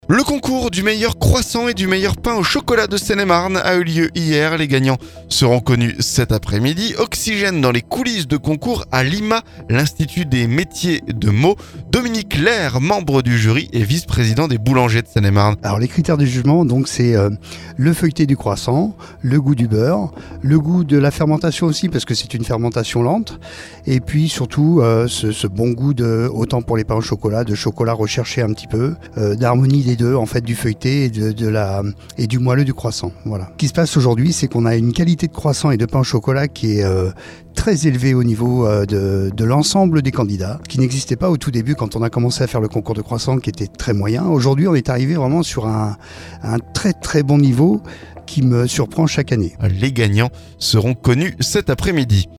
Oxygène dans les coulisses du concours à l'IMA de Meaux.